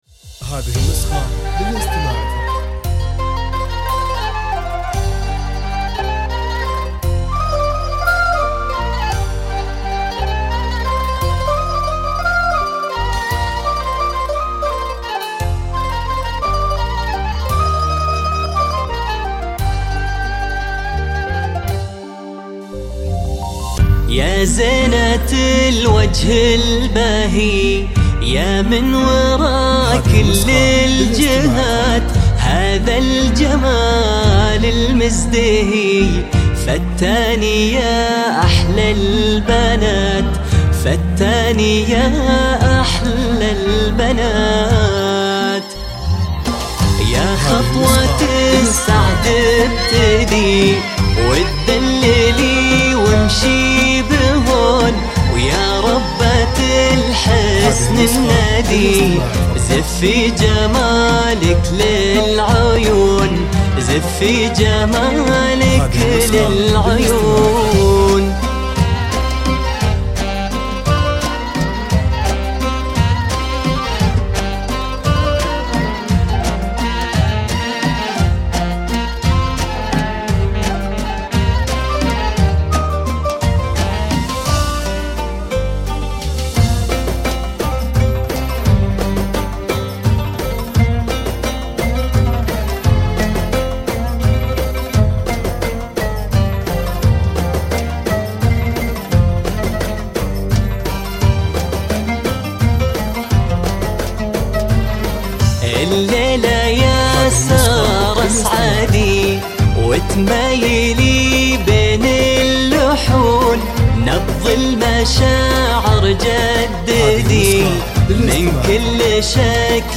زفات موسيقى
• الزفات الموسيقية التقليدية